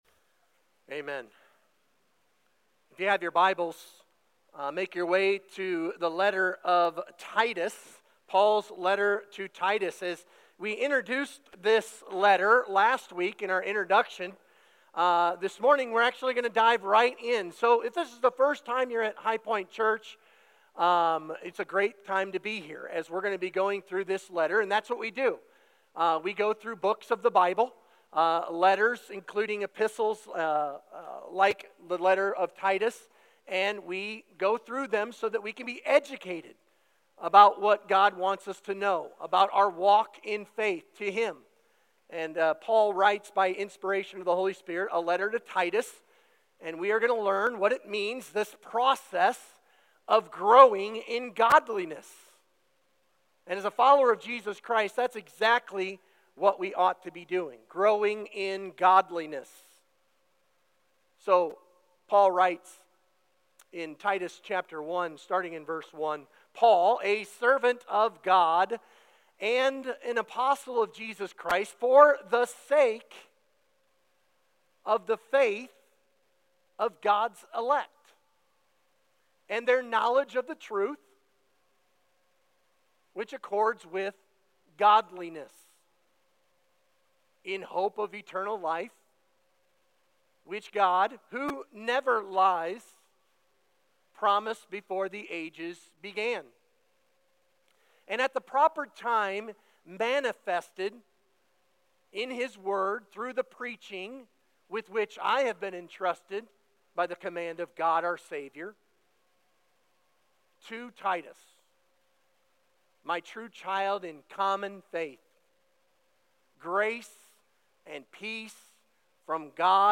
Sermon Questions Read Together Acts 20:18–24 Titus 1:1–4 Discussion & Scripture Engagement 1.